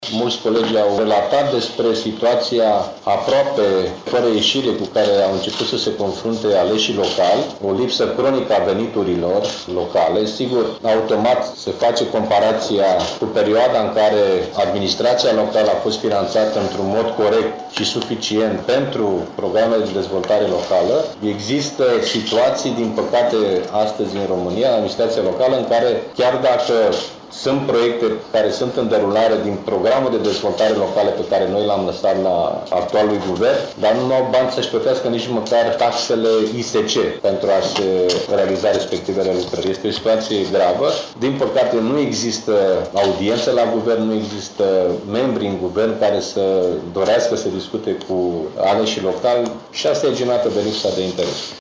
După lucrări, preşedintele formaţiunii, Liviu Dragnea, a susţinut o conferinţă de presă, în care a prezentat principalele subiecte dicutate.